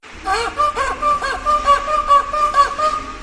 Strange McDonalds Bird